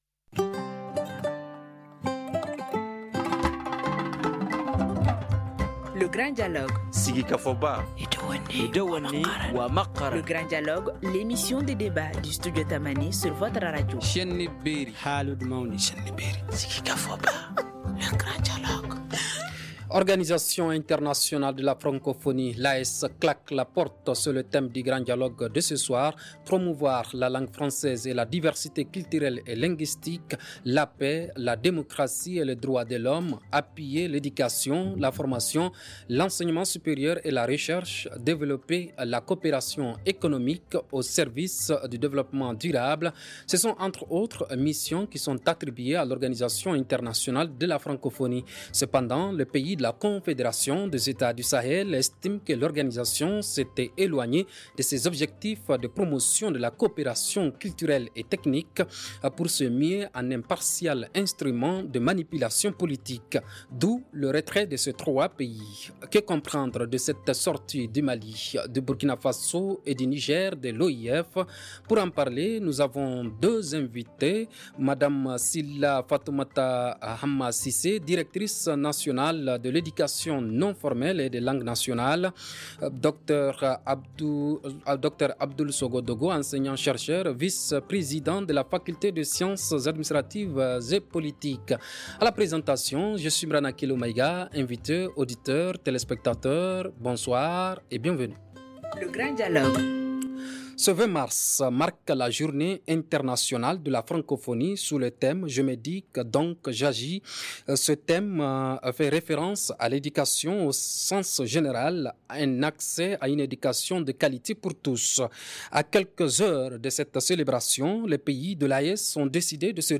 Pour en parler, nous avons trois invités :